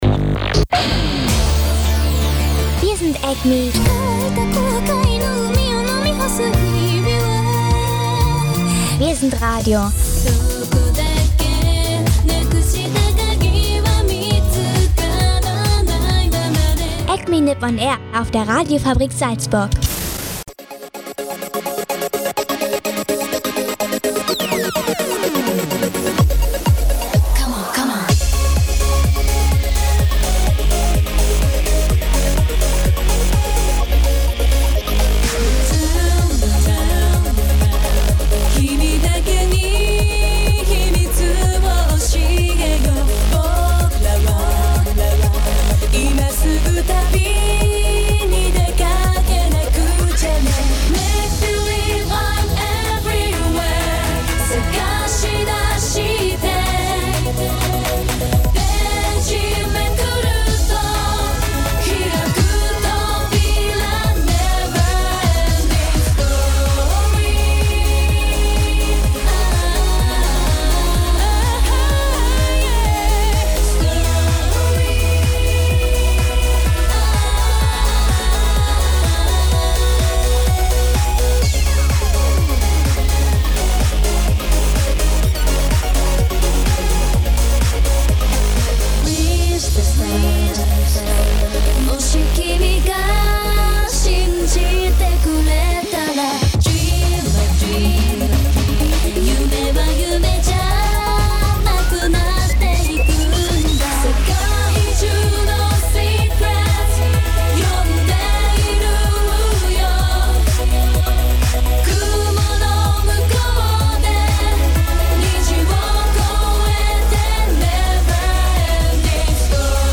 Gemischt mit guter Musik aus Japan.